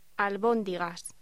Locución: Albóndigas